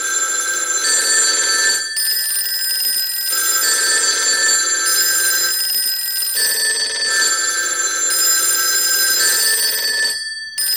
Index of /90_sSampleCDs/E-MU Producer Series Vol. 3 – Hollywood Sound Effects/Human & Animal/Phone Collage
PHONE COLL01.wav